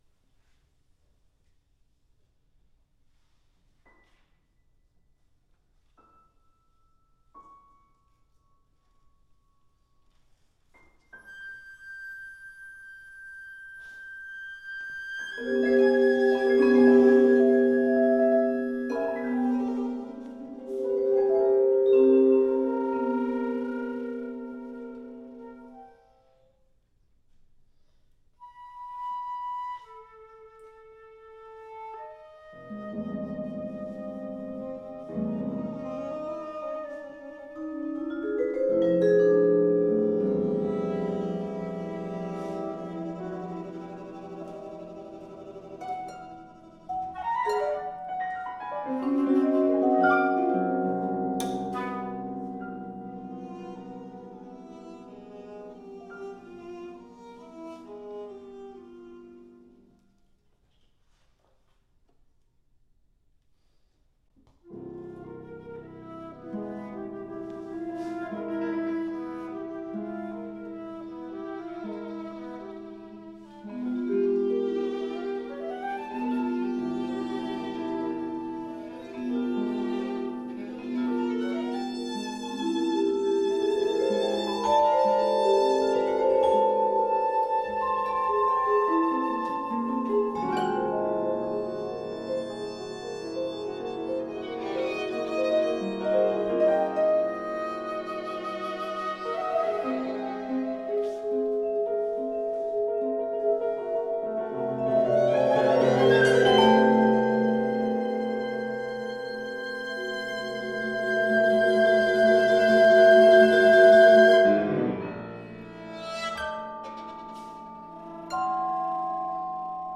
Recording from the live performance
flute
violin
vibraphone
harp
piano